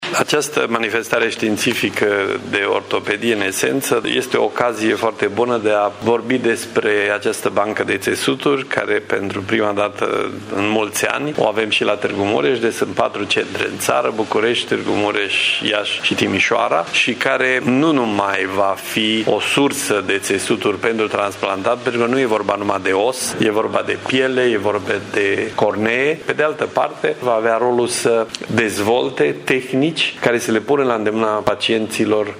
Pe viitor, acest tip de intervenții vor fi mai accesibile, odată cu construirea la Tîrgu-Mureș a Băncii de Țesuturi. Vor fi realizate inclusiv terapii de regenerare a țesuturilor, spune președintele Agenției Naționale de Transplant, prof.dr. Radu Deac: